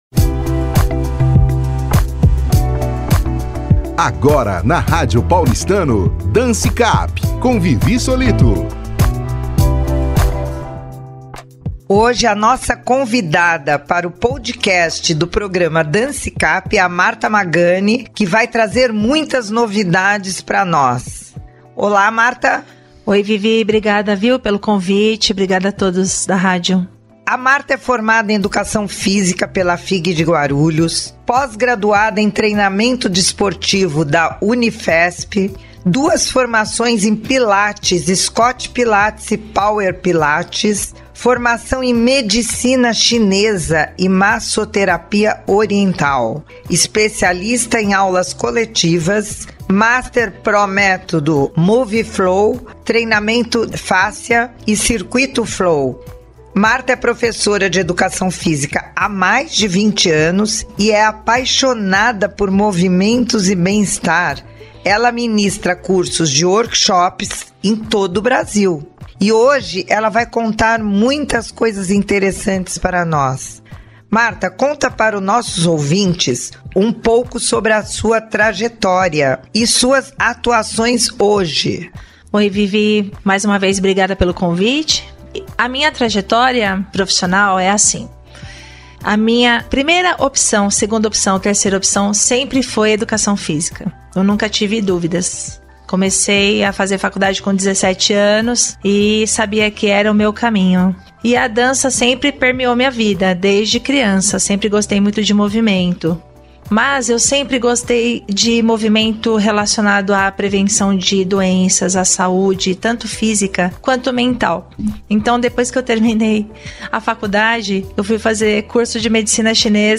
DANCE CAP – ENTREVISTA